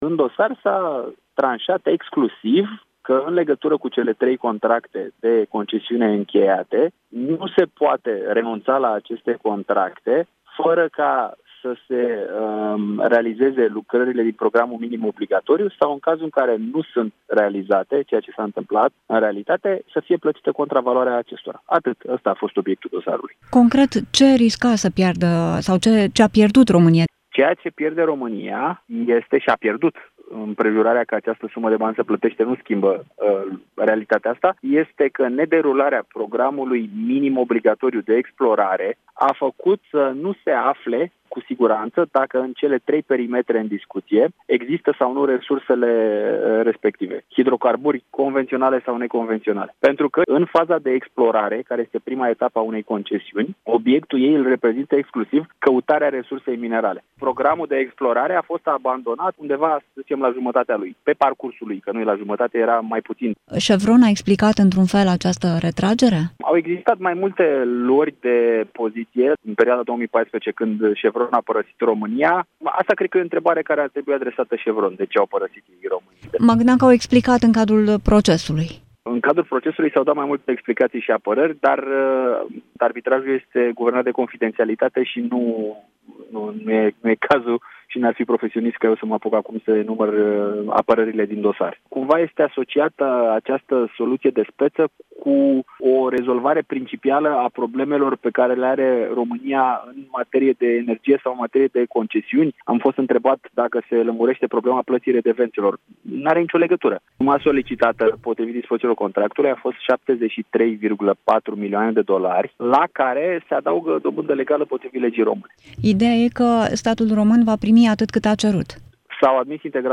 interviu-CHEVRON.mp3